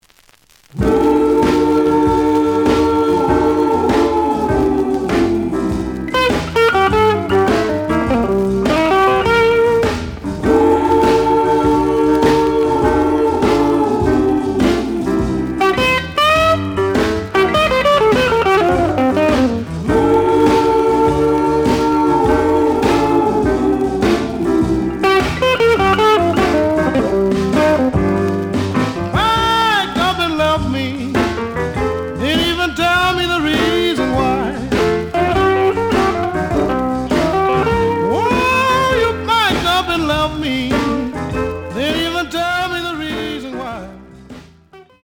The audio sample is recorded from the actual item.
●Genre: Blues
Looks good, but slight noise on both sides.)